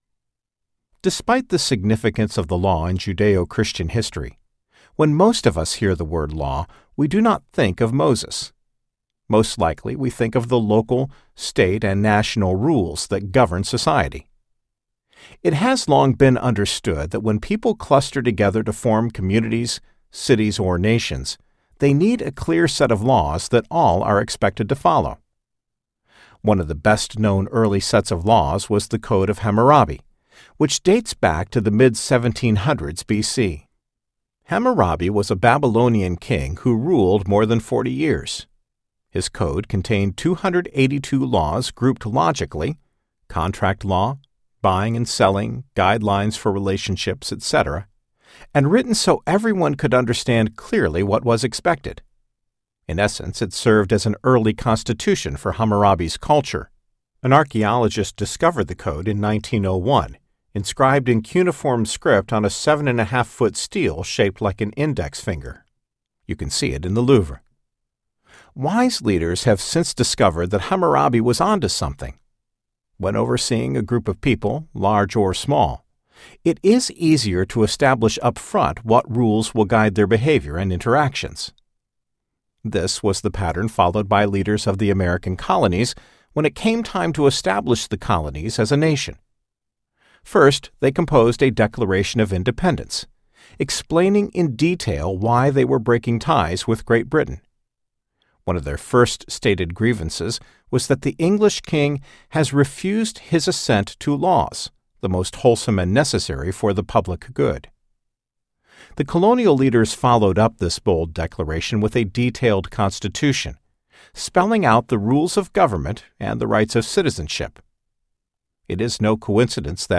One Nation without Law Audiobook
Narrator
6.28 Hrs. – Unabridged